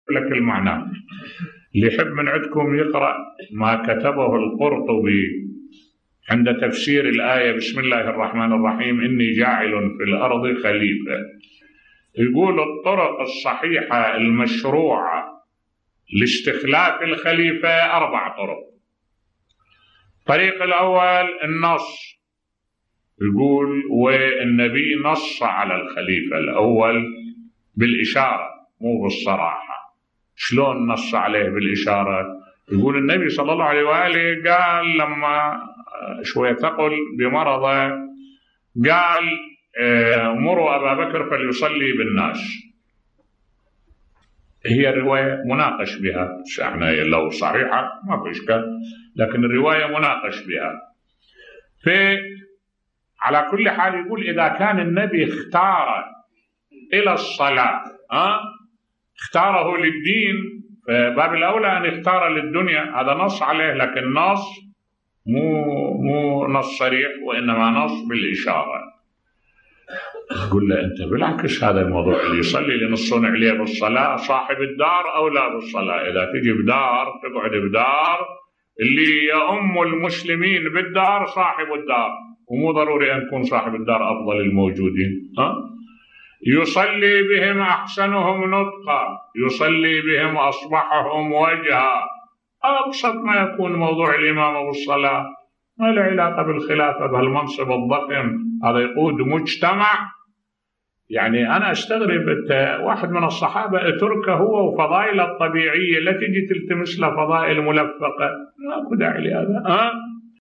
ملف صوتی الرد على القرطبي في دعواه النص على خلافة ابي بكر بصوت الشيخ الدكتور أحمد الوائلي